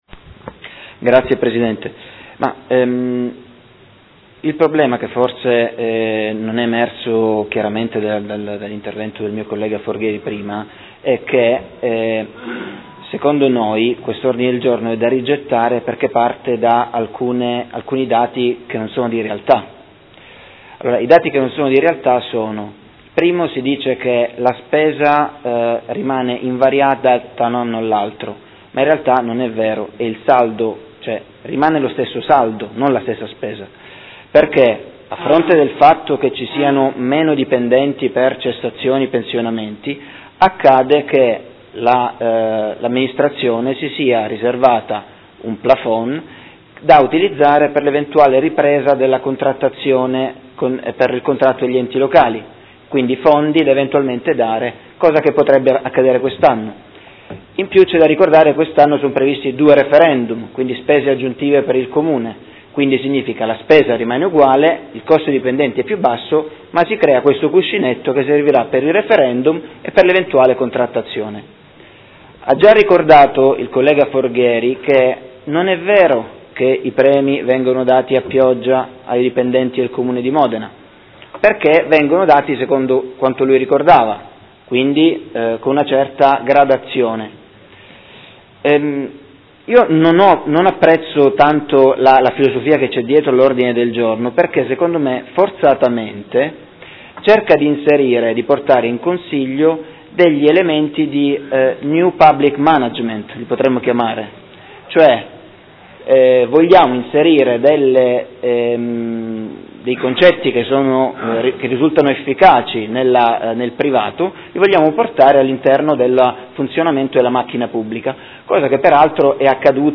Seduta del 14/04/2016 Dibattito.